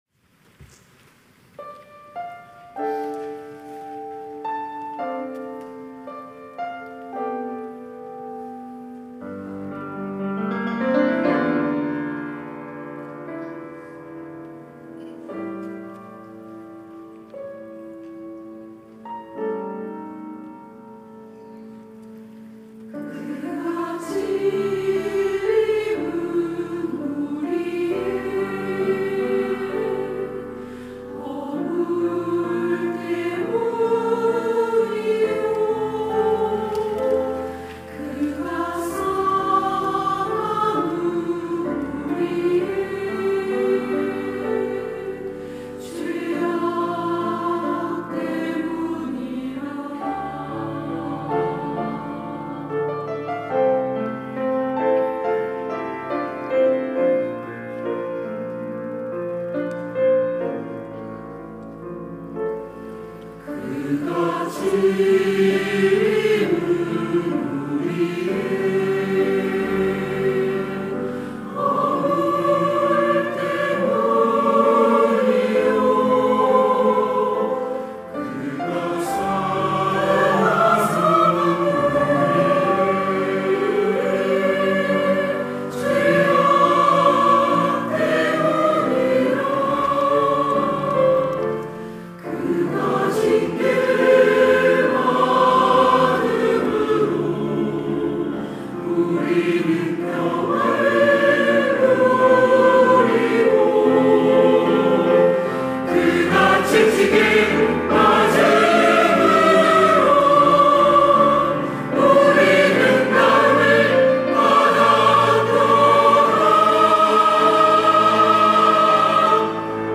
시온(주일1부) - 그가 찔림은
찬양대